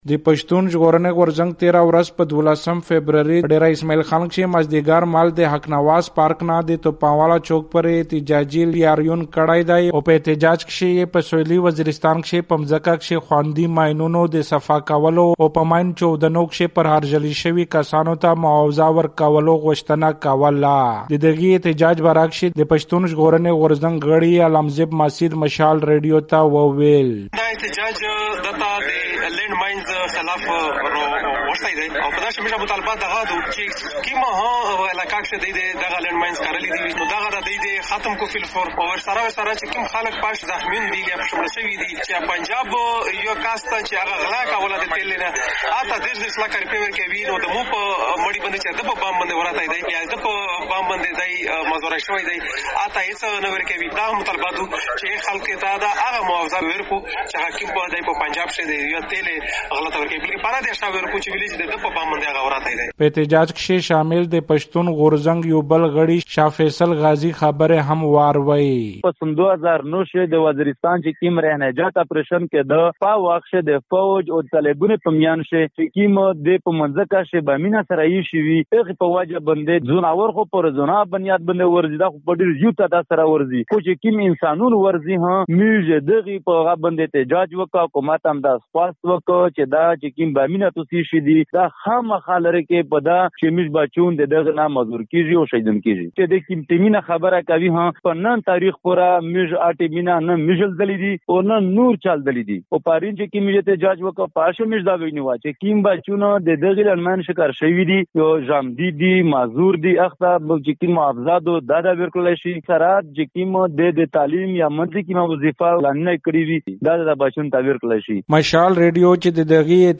ډېره اسماعیل خان کې د پښتون ژغورنې غورځنګ احتجاجي مظاهره